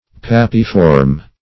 pappiform - definition of pappiform - synonyms, pronunciation, spelling from Free Dictionary Search Result for " pappiform" : The Collaborative International Dictionary of English v.0.48: Pappiform \Pap"pi*form\, a. (Bot.) Resembling the pappus of composite plants.